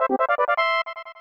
とうとうエンカウント音までつきだしたか…